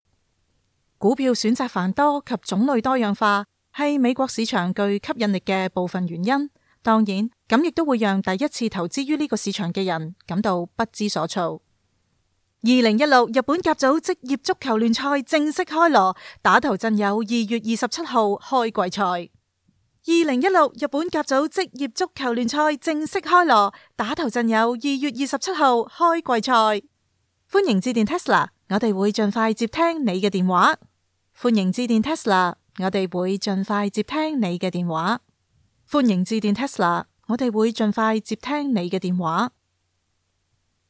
Female
My voice is warm, friendly, clear, gentle, enthusiastic, firm, and sweet, with the versatility to perform across e‑learning, narration, commercial work, character voice acting, and more.
Warm And Friendly Cantonese Female Voice Talent For Commercial And Corporate Recordings
All our voice actors have professional broadcast quality recording studios.